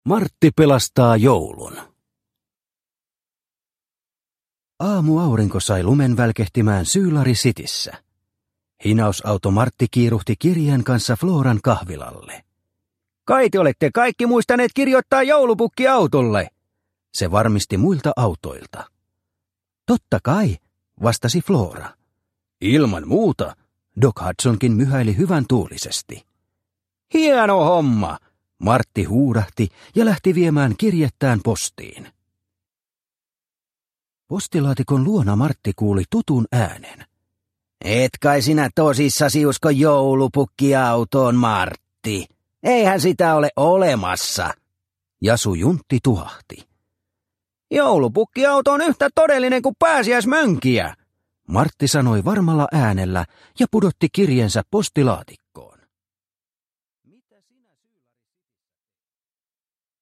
Martti pelastaa joulun – Ljudbok – Laddas ner